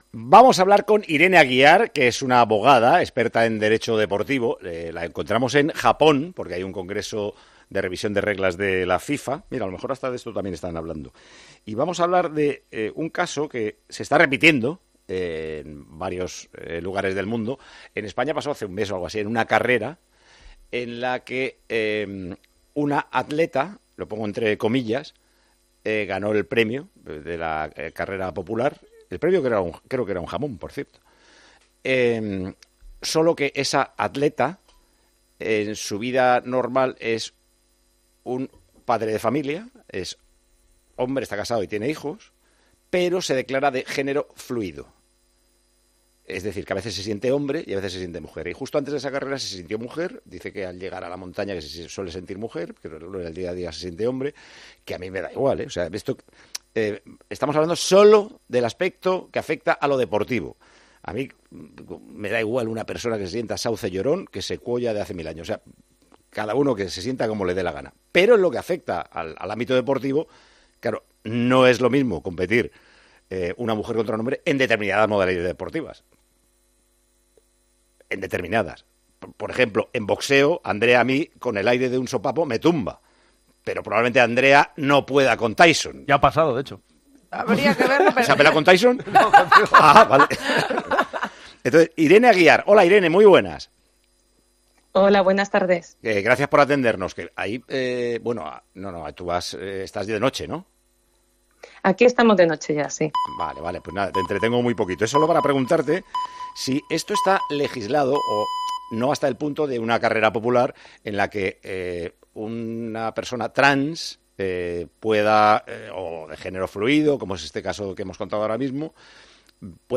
Hablamos con la abogada deportiva